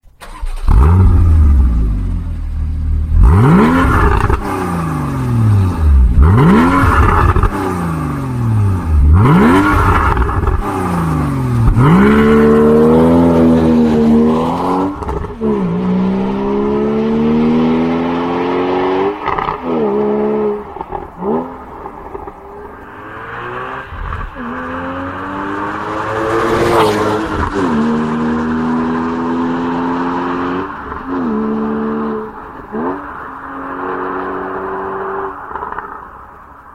Instead, I focused on adding options that enhanced the Boxster's best features - handling and the sound of the fabulous flat six engine that produces what I call the Symphony in Flat Six.
• Porsche Sports Exhaust  -